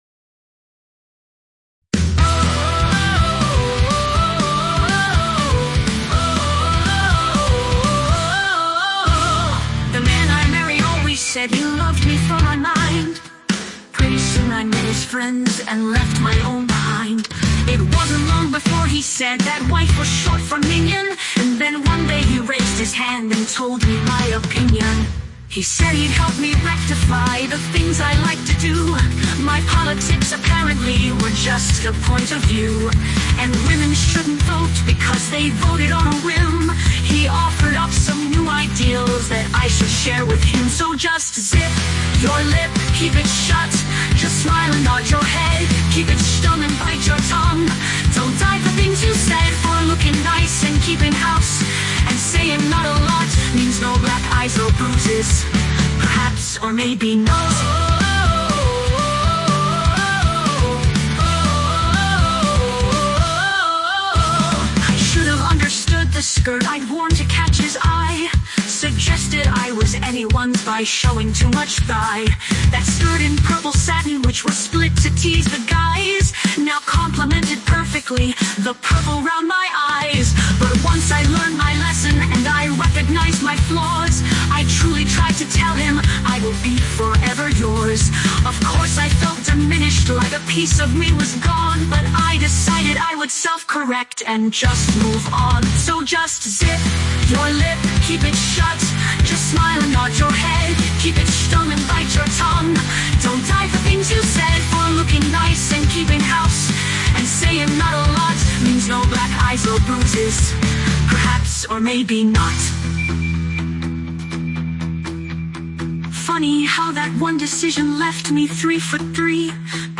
Lyrics are human
Music performed by Bits and Bytes and Fairy Lights
(Okay... I mean AI )